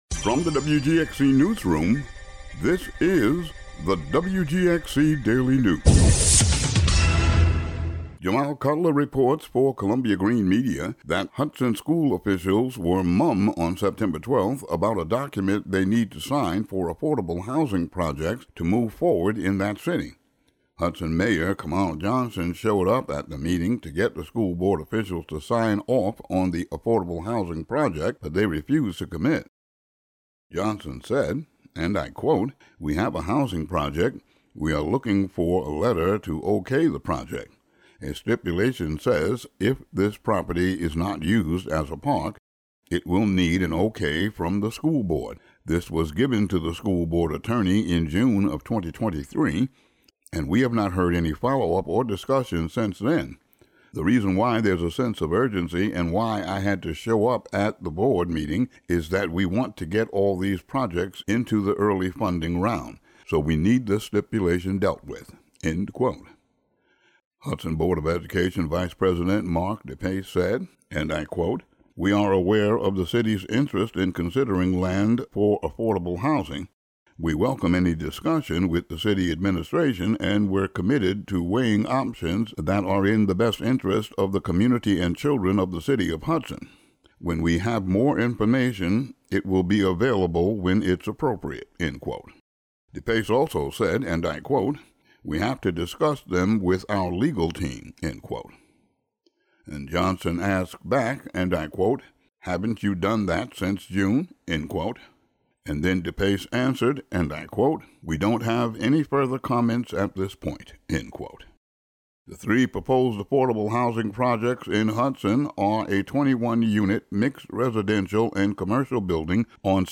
Today's daily news audio update.